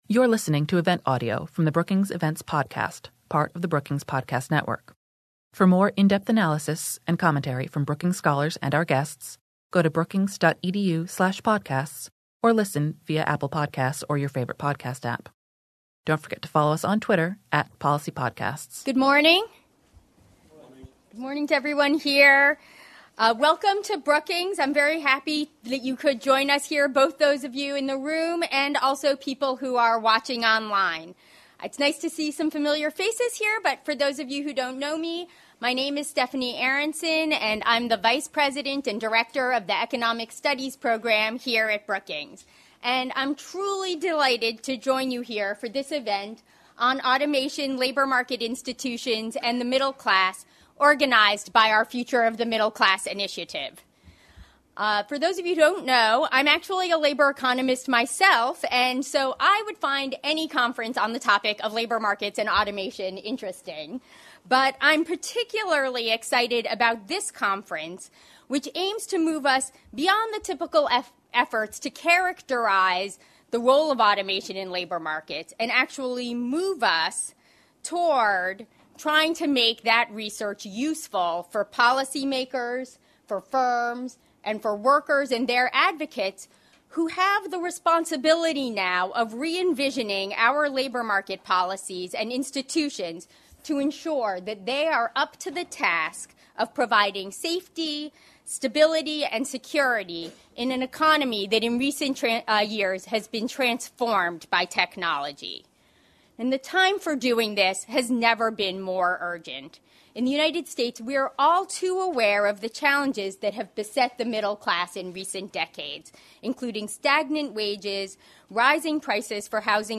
On Thursday, December 12, the Future of the Middle Class Initiative at the Brookings Institution hosted an event focused on how advances in automation and AI affect and interact with labor force decisions and key labor market features such as minimum wages, the earned income tax credit, and unions.
Presentation: A tale of two workers – The macroeconomics of automation